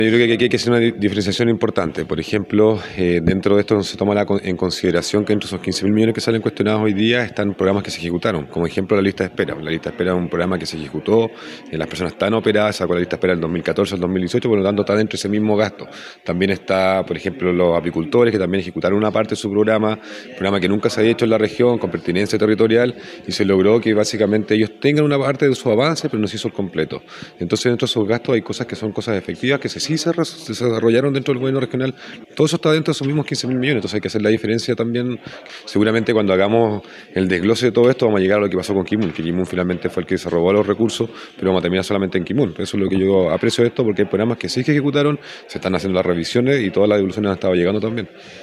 En esa misma línea, el Core de la provincia de Llanquihue, Rodrigo Arismendi, señaló que es necesario hacer las diferenciaciones pertinentes.